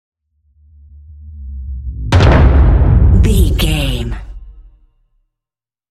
Dramatic whoosh to hit drum
Sound Effects
In-crescendo
Thriller
Atonal
intense
tension
woosh to hit